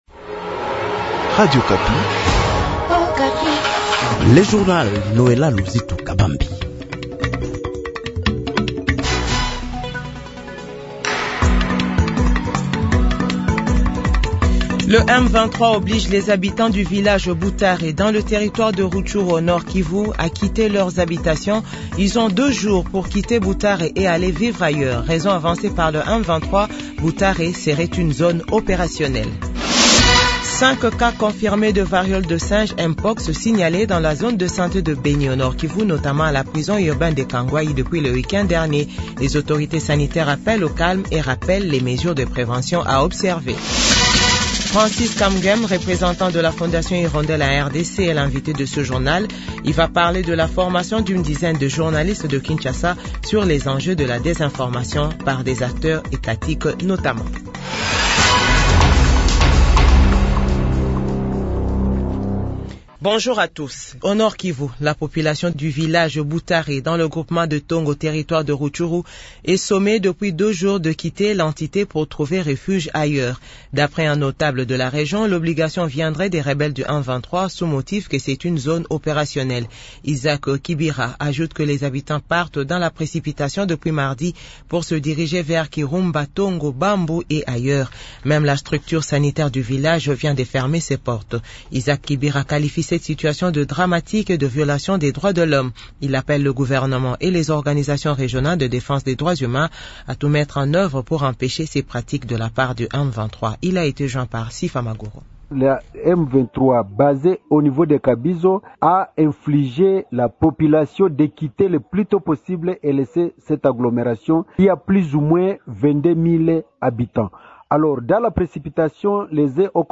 JOURNAL FRANÇAIS DE 8H00